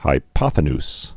(hī-pŏthə-ns, -nys)